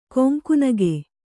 ♪ koŋku nage